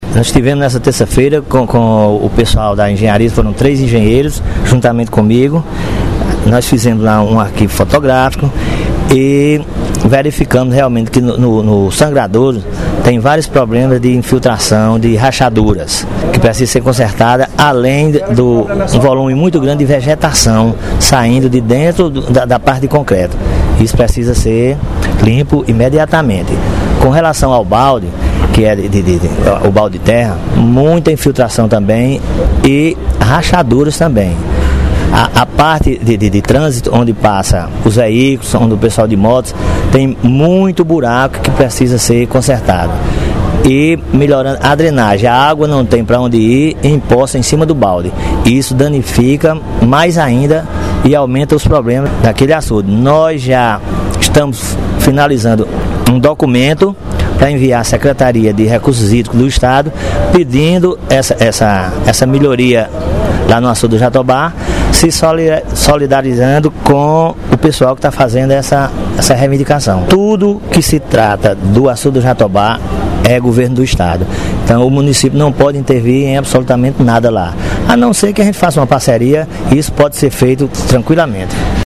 • Fala do secretário municipal de Infraestrutura, Antônio Carlos (Lito)